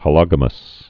(hə-lŏgə-məs)